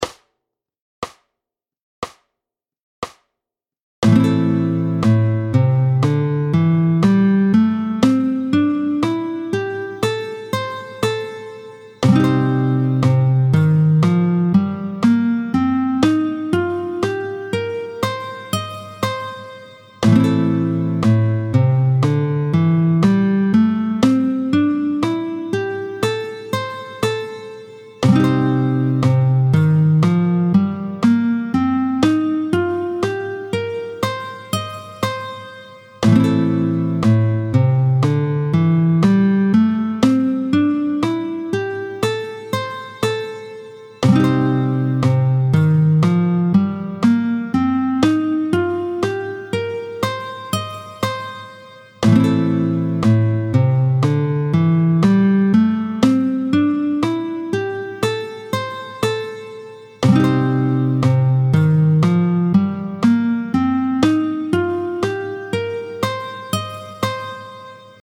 32-07 De lam à Dom, tempo 70
32-07-de-la-a-do-mineur.mp3